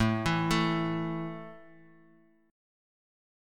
A5 Chord
Listen to A5 strummed